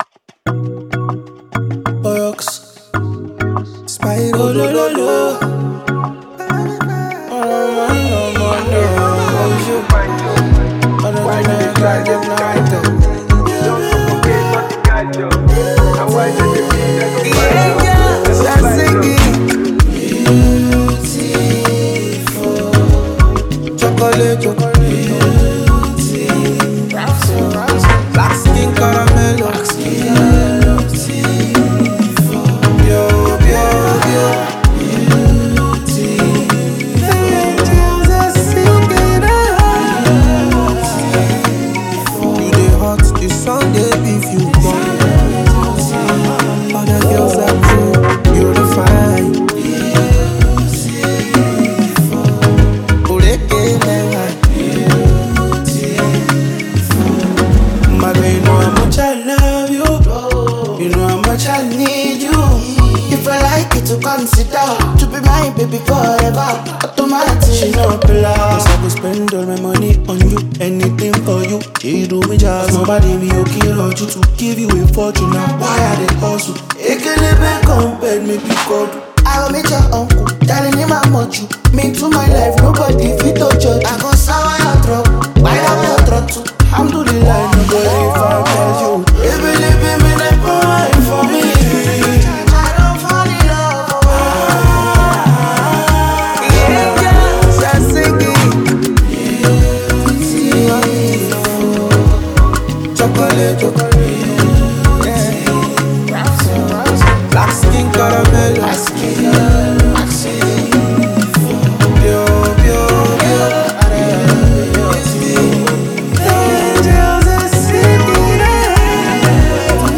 two Nigerian acts